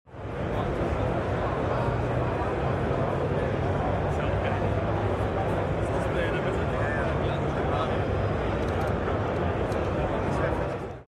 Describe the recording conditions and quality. in the wild at the Simracing Expo 2023 in Dortmund